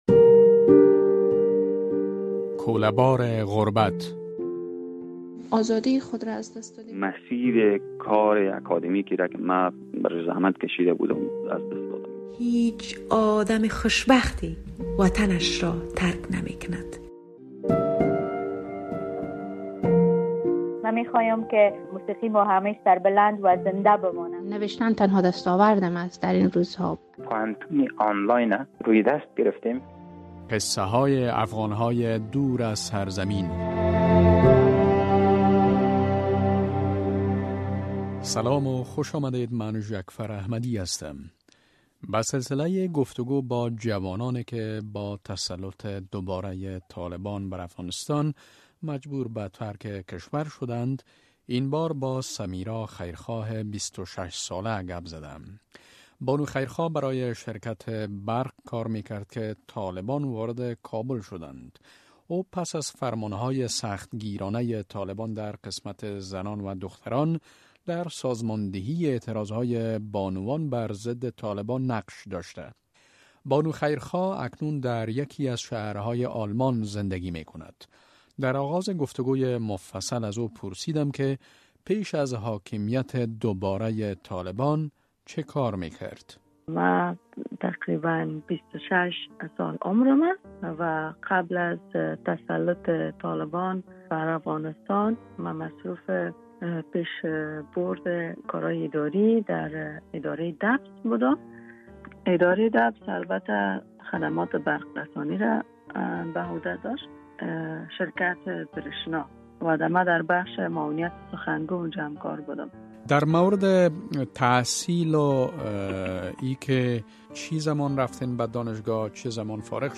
رادیو آزادی سلسله‌ای از گفت‌وگو های جالب با آن عده از شهروندان افغانستان را آغاز کرده است که پس از حاکمیت دوبارۀ طالبان بر افغانستان، مجبور به ترک کشور شده اند.